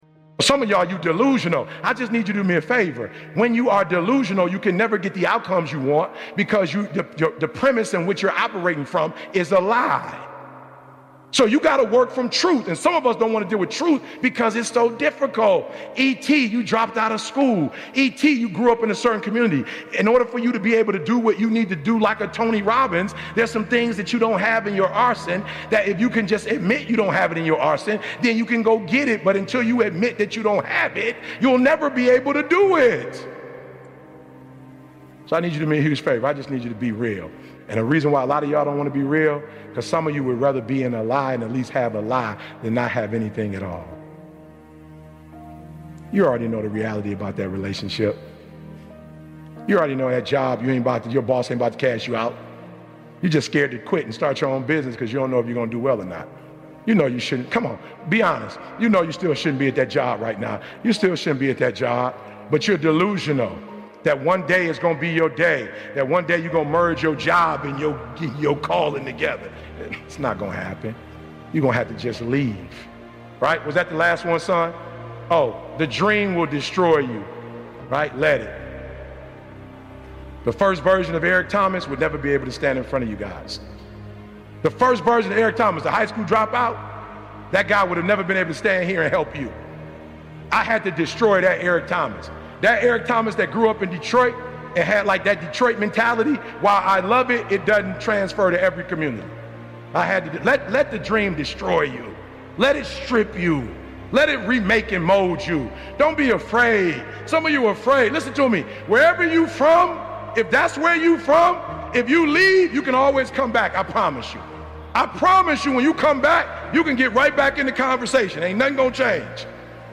Eric Thomas - Never stop grinding motivational speech
Get ready for a high-energy episode featuring the passionate Eric Thomas in "Never Stop Grinding." In this powerful conversation, ET shares his relentless approach to success and the mindset required to keep pushing forward, no matter how tough it gets.